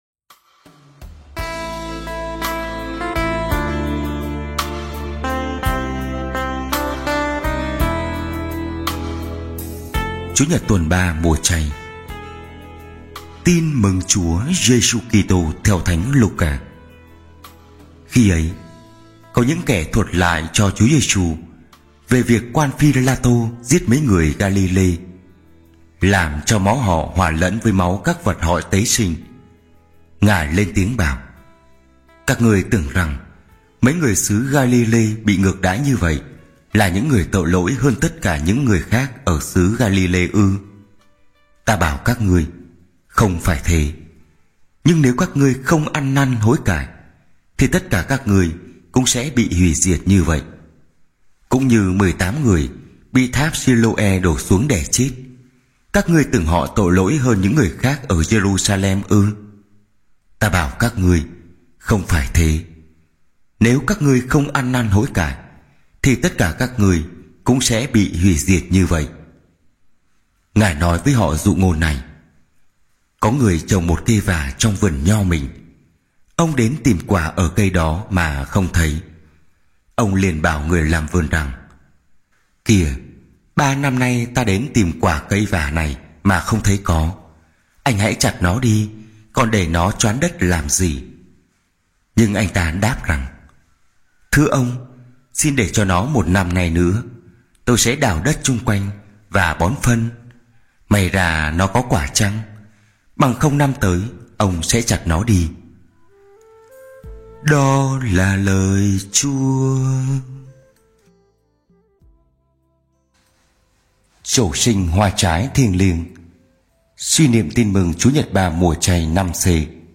Bài giảng lễ Chúa nhật 3 mùa chay - 2025